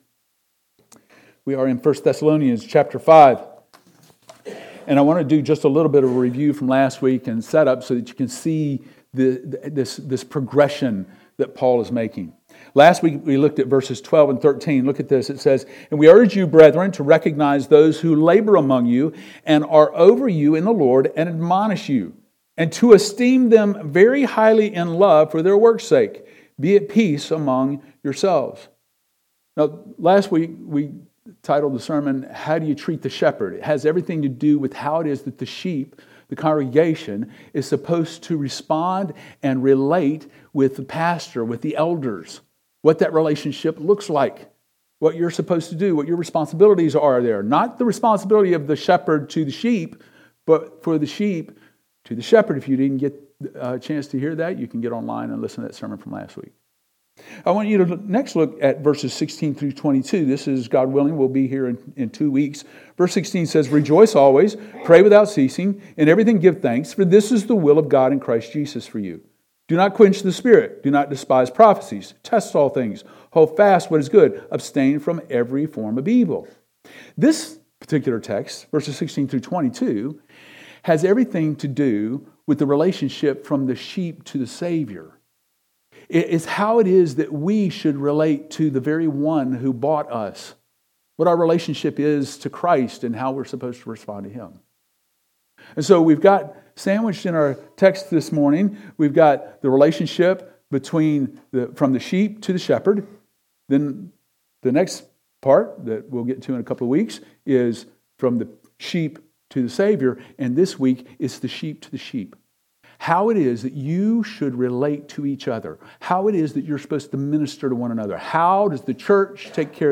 3-1-26-sermon-How-Do-You-Treat-The-Sheep.mp3